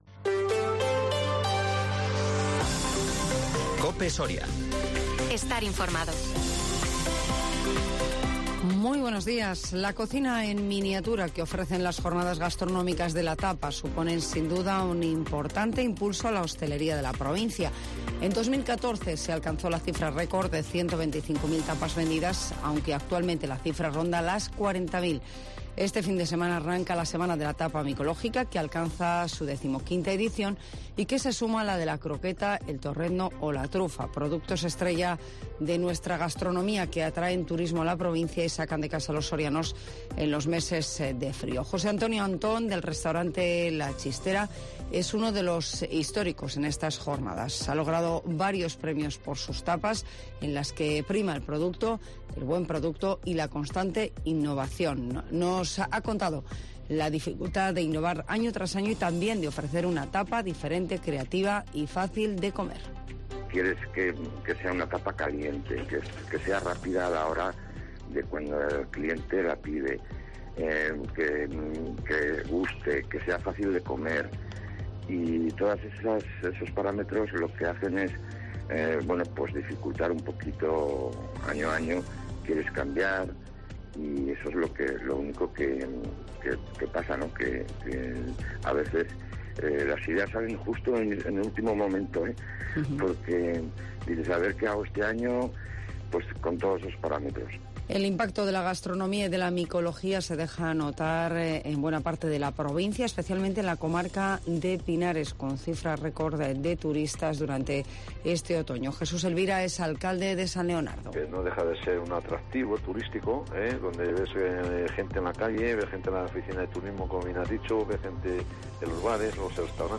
Las noticias en COPE Soria